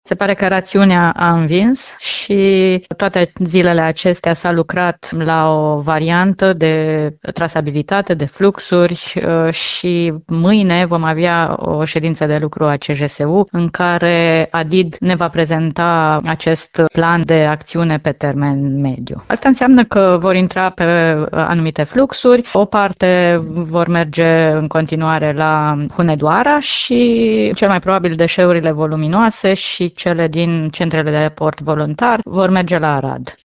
Problema deșeurilor voluminoase și colectate din rampe clandestine din Timișoara a fost rezolvată, susține prefectul de Timiș.